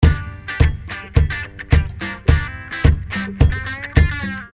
гитара
без слов
Здорово играет